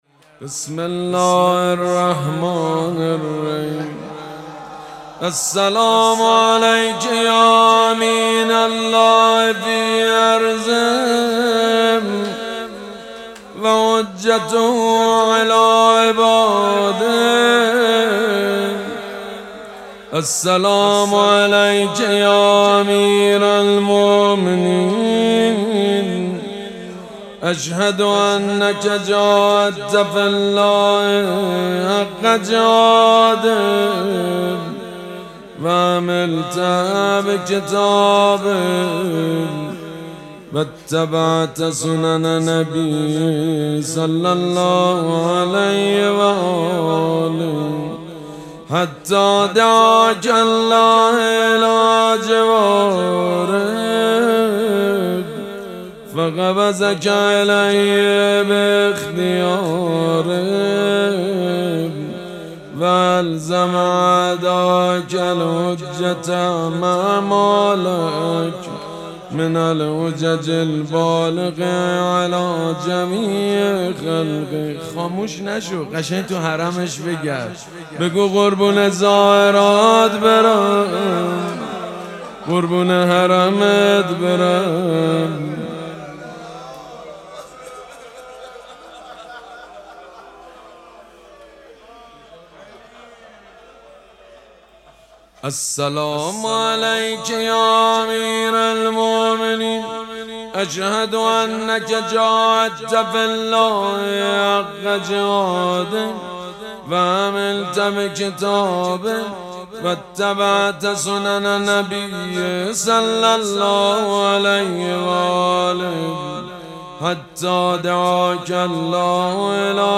شب پنجم مراسم هشت شب عاشقی ماه رمضان
مناجات
مداح